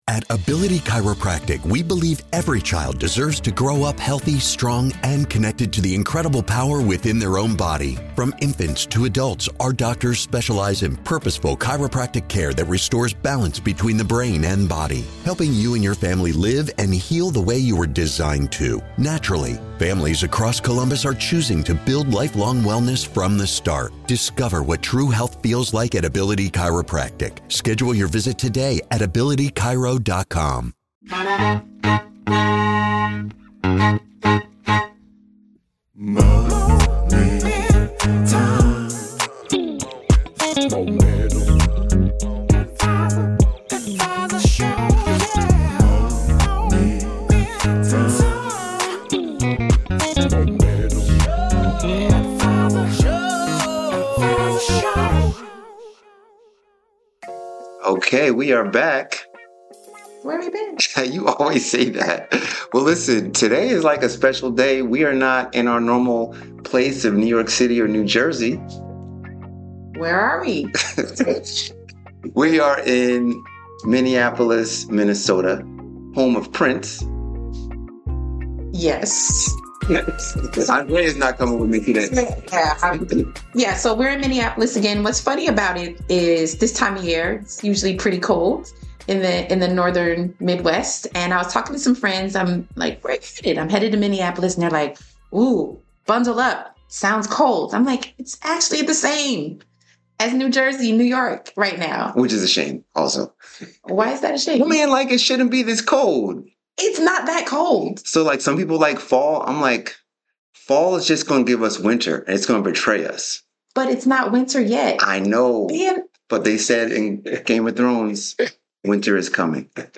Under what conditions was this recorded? We come to you today from “The Collective Sum,” a gathering of Black community leaders in Minneapolis. Join us in a conversation about community building through investing in “the Four ‘Ships” — Leadership, Authorship, Stewardship and Ownership.